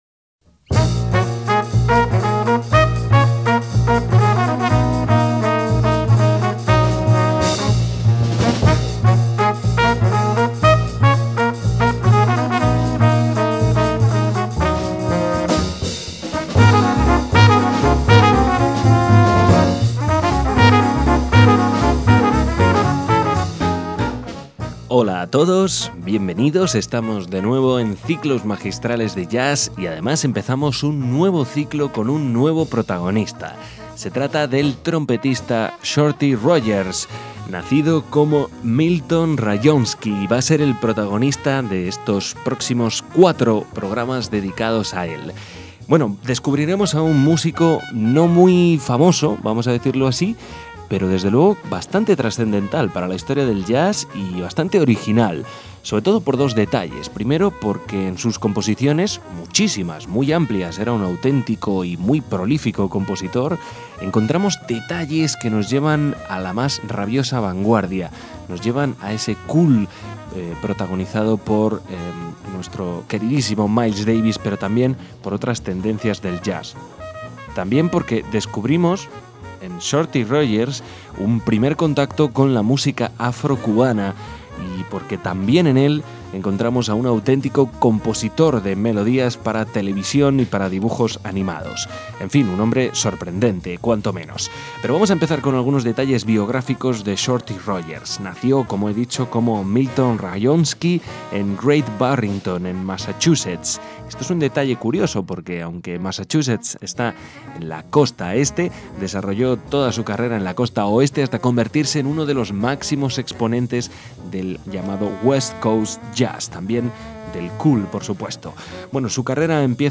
De momento, lo escucharemos con su quinteto de medidados de los años 50.